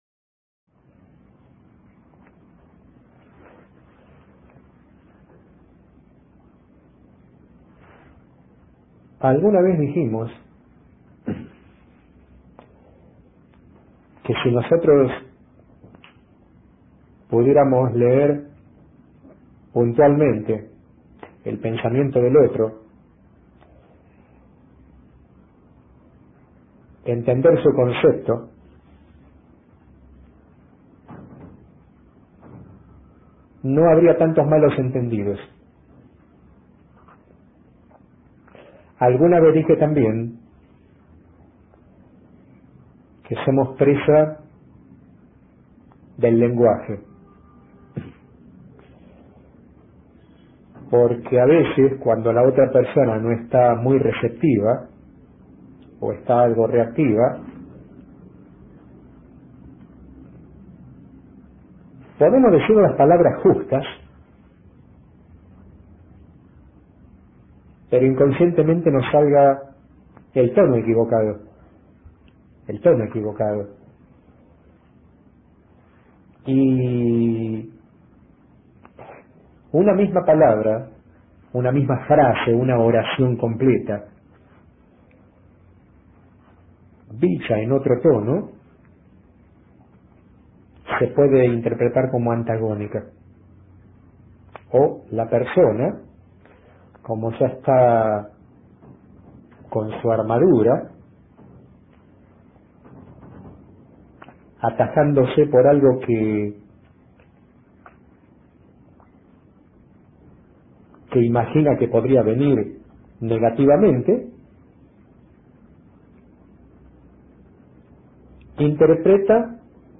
Extensa charla en la que se habló sobre la verdadera tolerancia -que nace de la comprensión del otro- y de cómo manejar la comunicación con personas reactivas. La expectación genera ansiedad y frustración.